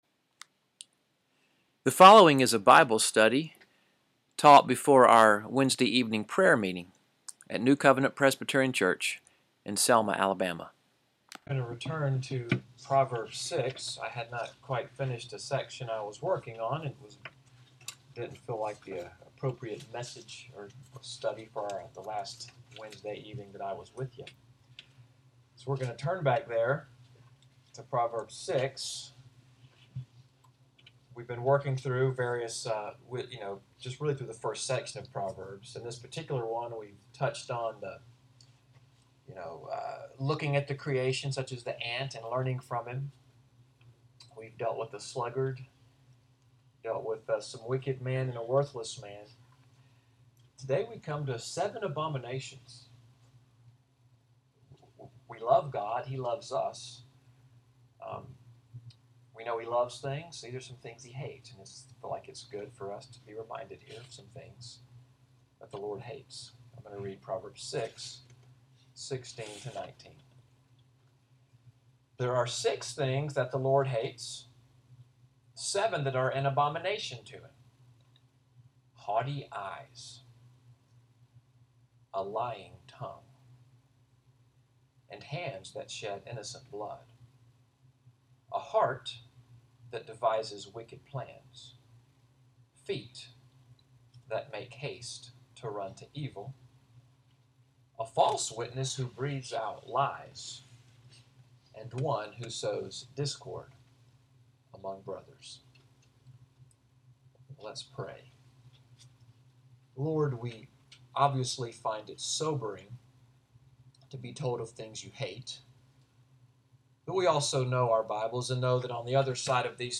Wednesday Night Bible Study before Prayer at NCPC. June 14, 2017